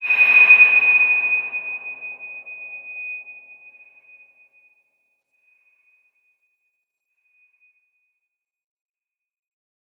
X_BasicBells-D#5-mf.wav